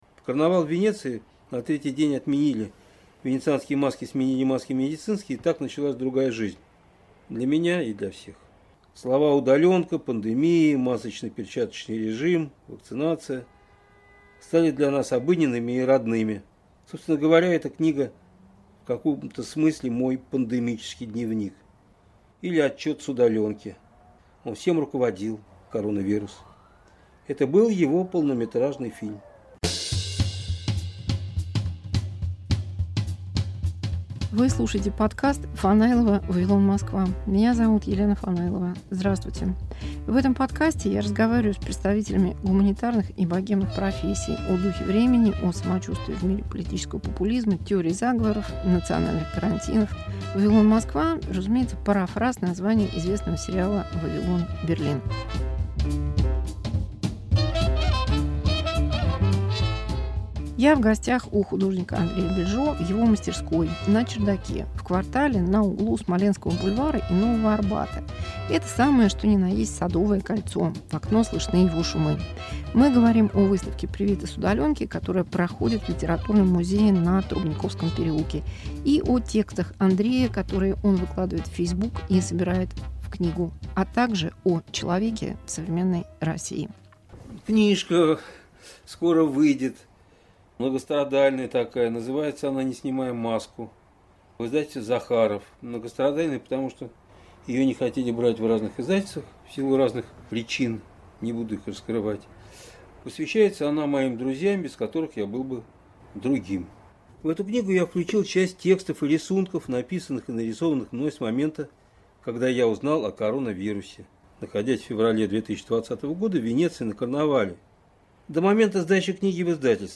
Разговор с психиатром, художником, писателем. Карантин и политика, люди и бесчеловечность